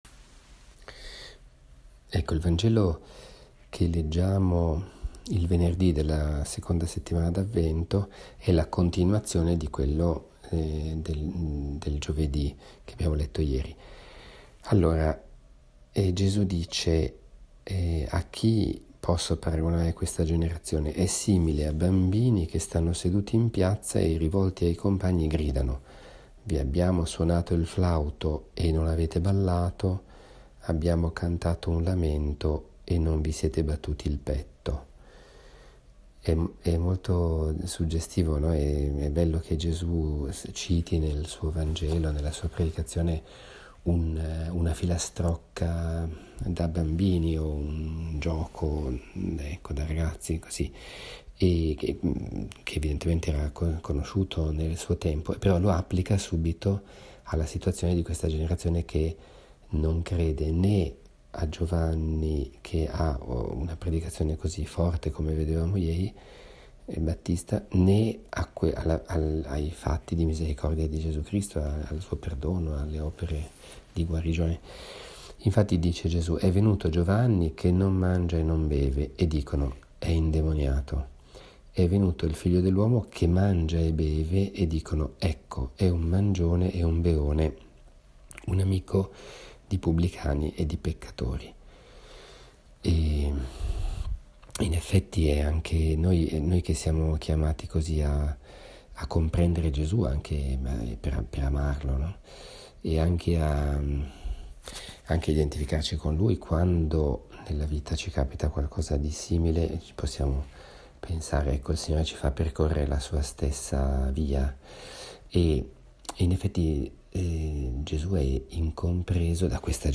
Commento al vangelo di domani, venerdì 15 dicembre, II venerdì del tempo di Avvento.